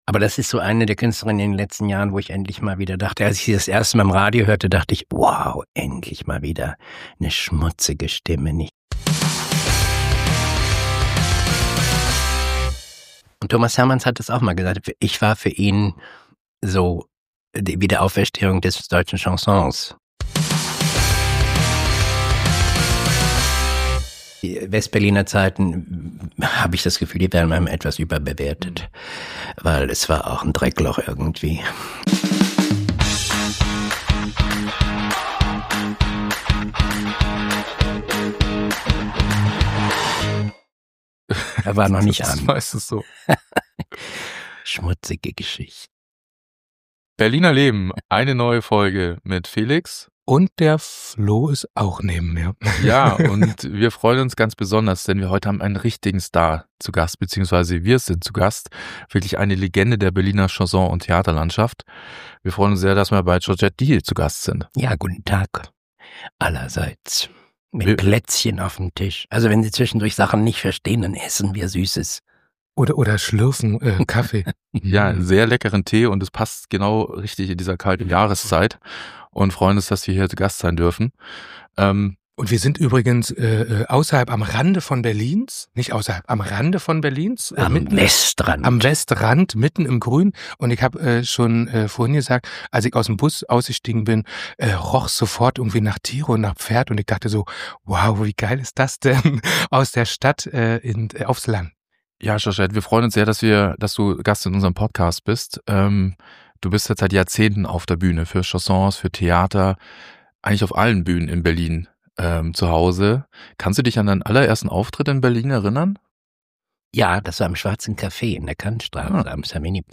Beschreibung vor 3 Monaten In unserer bislang längsten Folge treffen wir Georgette Dee in hrer Küche.